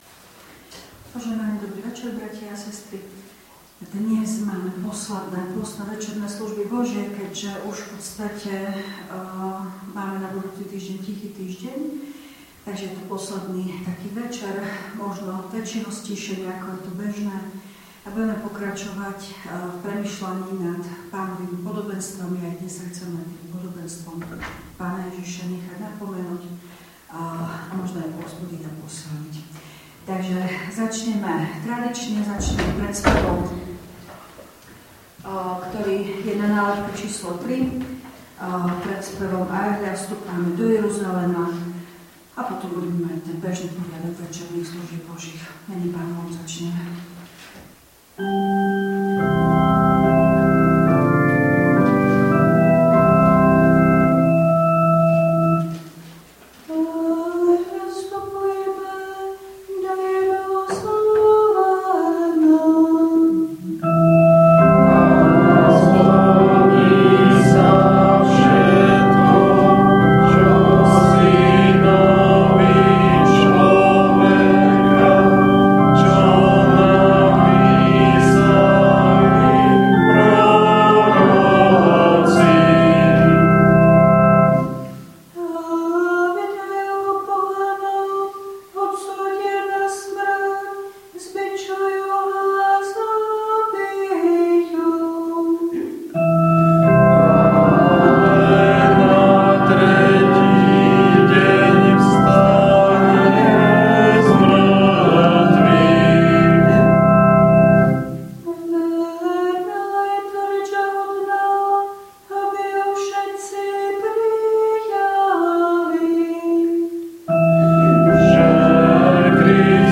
Pôstne večerné SB 24.3.2026
V nasledovnom článku si môžete vypočuť zvukový záznam z Pôstnych večerných SB 24.3.2026.